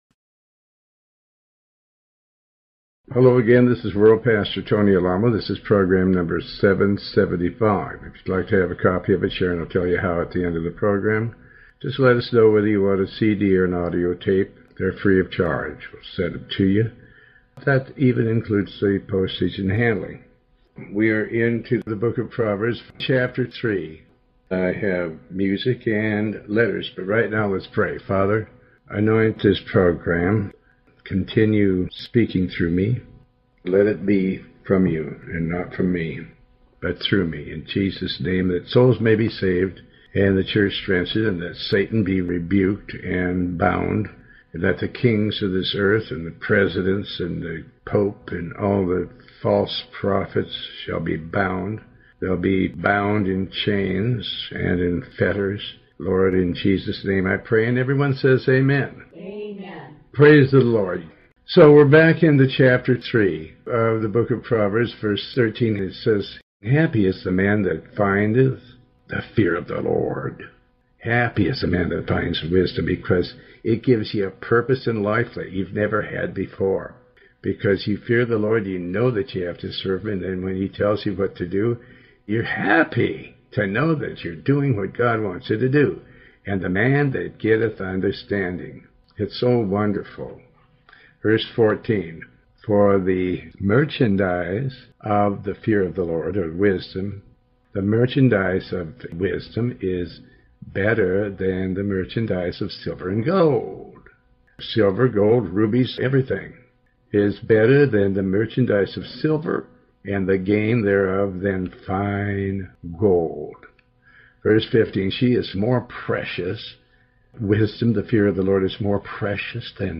In this program originally recorded in 2008, Pastor Alamo reads from and comments on the Book of Proverbs, chapter 2 verse 7 through chapter 4 verse 8. The program also contains letters and some music.